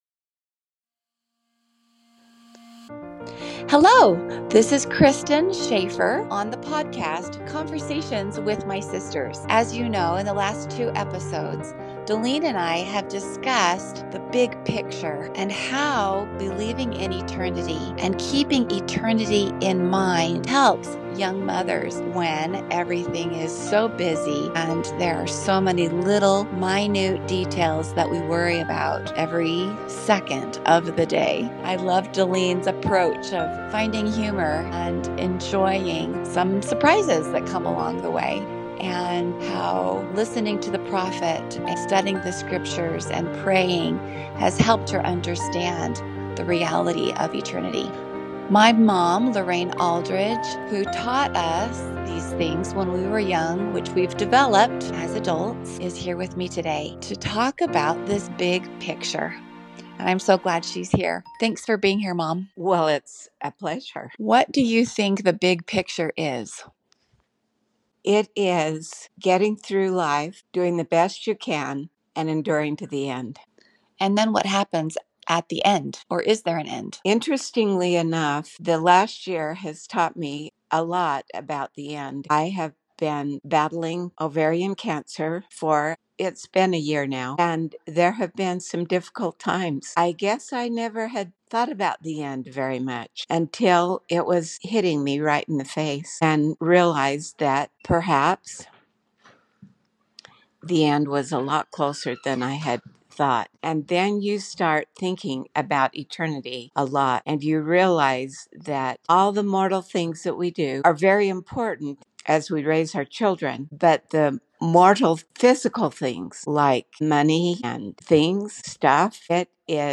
Conversations With My Sisters